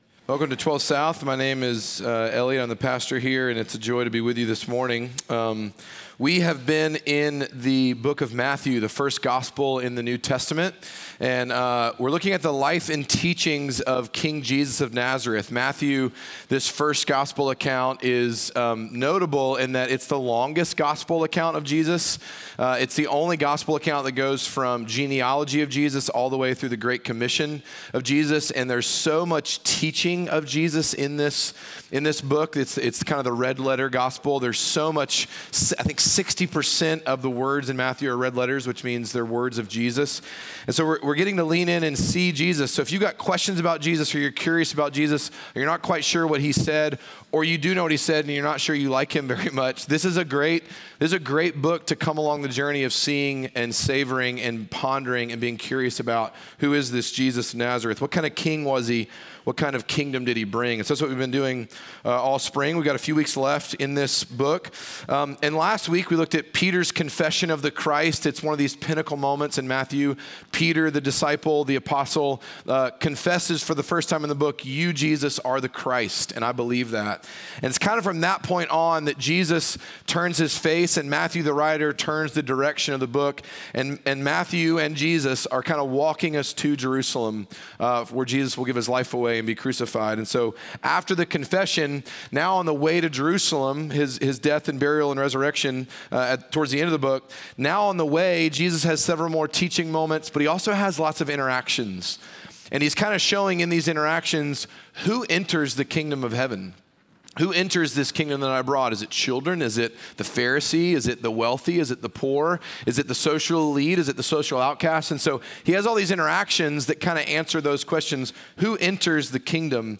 Midtown Fellowship 12 South Sermons The Rich Young Ruler May 12 2024 | 00:44:30 1x 00:00 / 00:44:30 Subscribe Share Apple Podcasts Spotify Overcast RSS Feed Share Link Embed